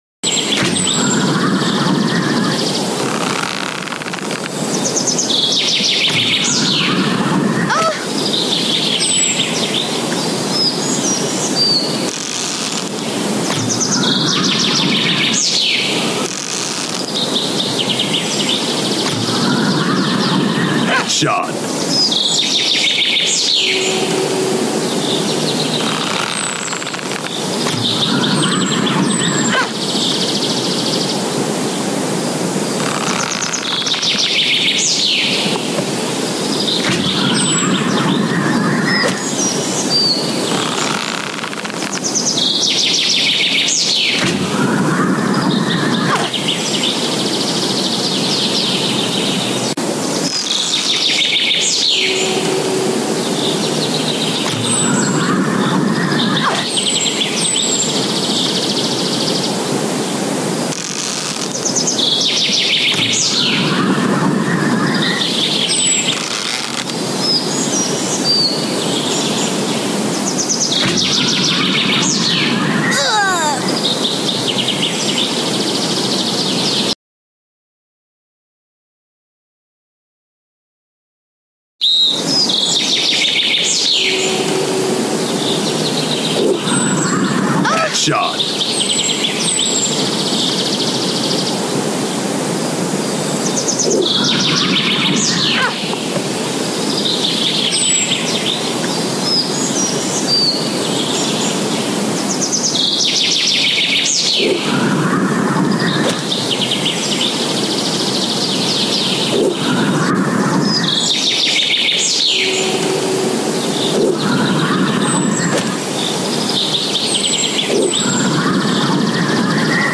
Archery Clash 🎯 Epic Bow Sound Effects Free Download